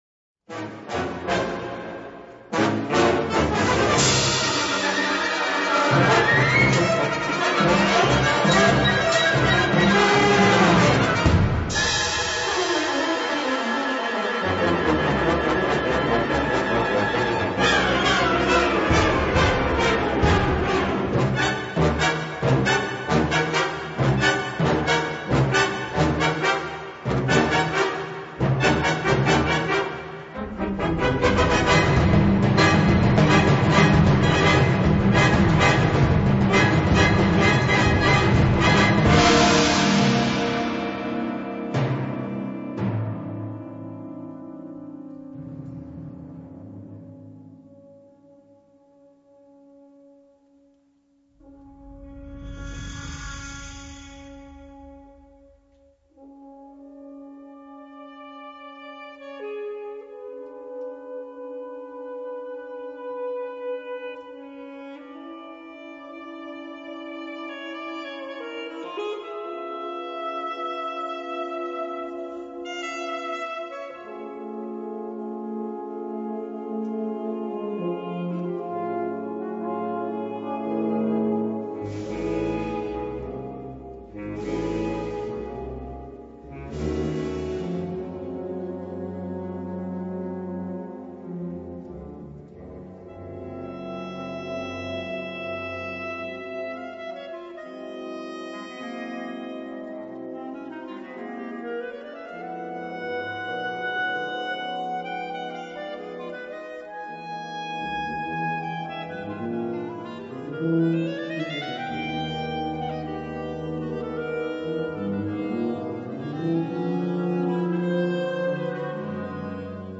Kategorie Blasorchester/HaFaBra
Unterkategorie Zeitgenössische Bläsermusik (1945-heute)
Besetzung Ha (Blasorchester)